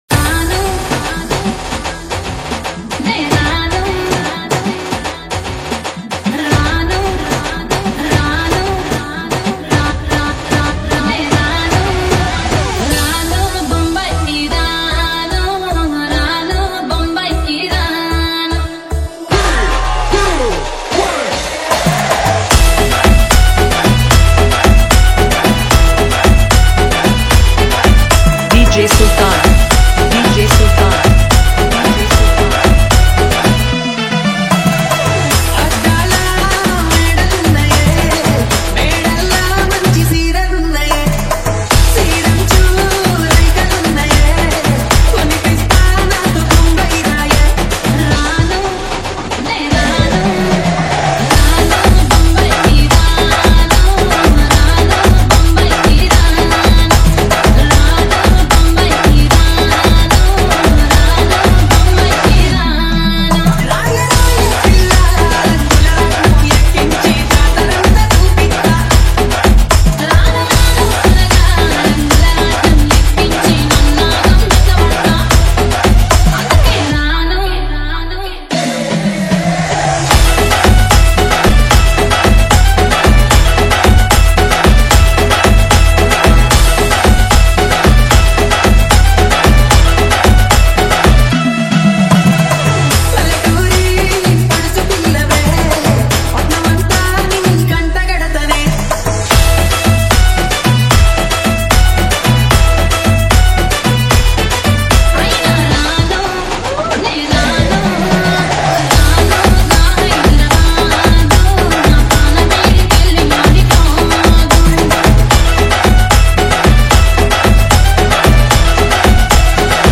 Telugu Love Dj Remix